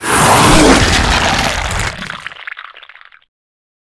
Bite.wav